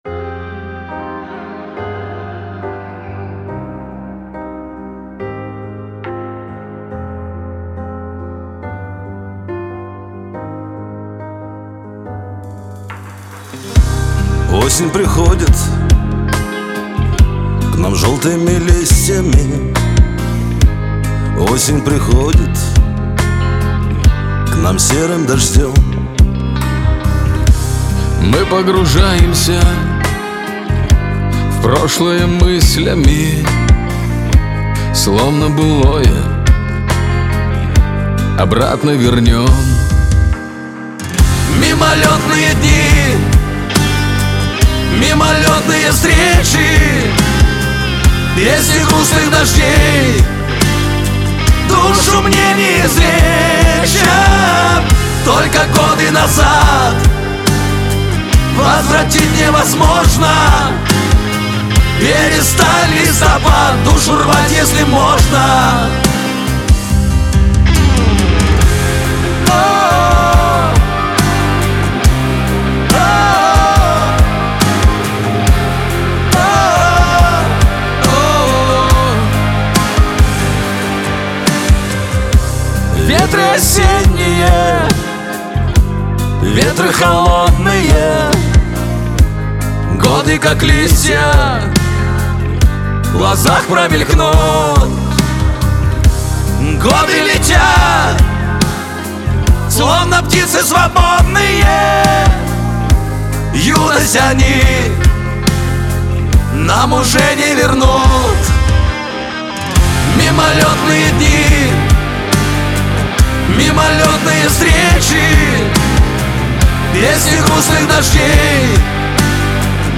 эстрада
pop
дуэт